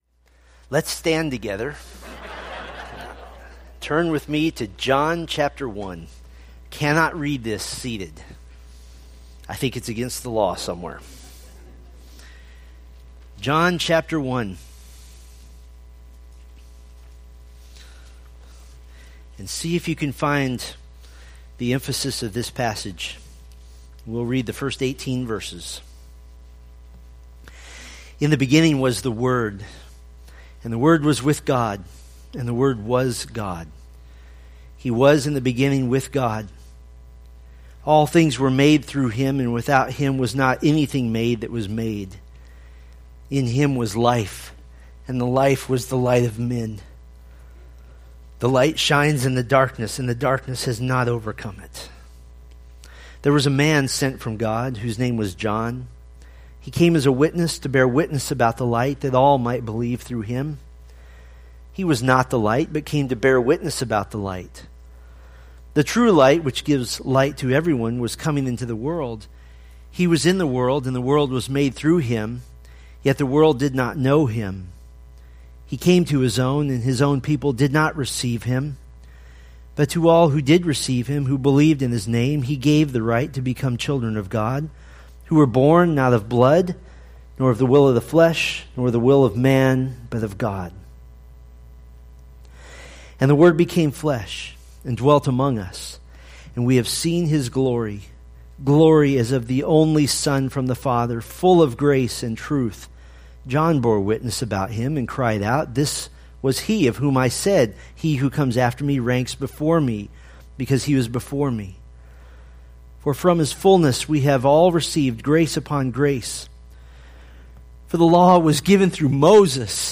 Preached September 25, 2016 from John 1:1-18